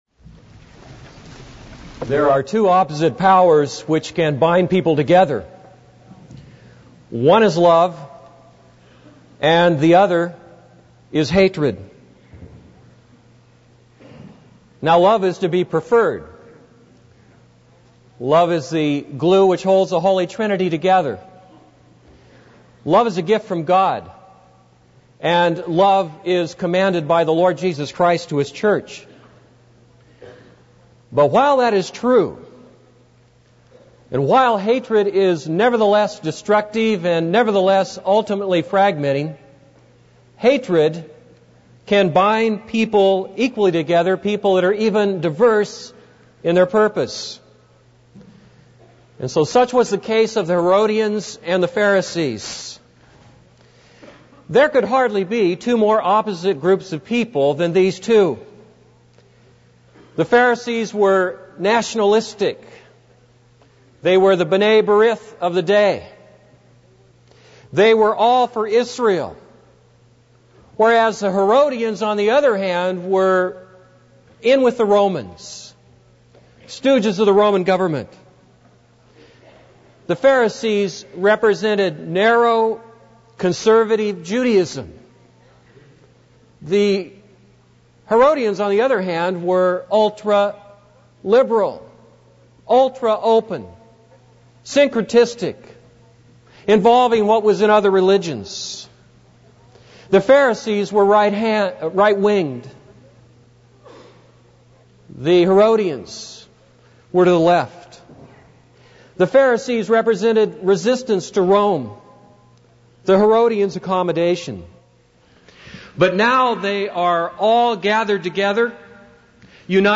This is a sermon on Mark 12:13-17.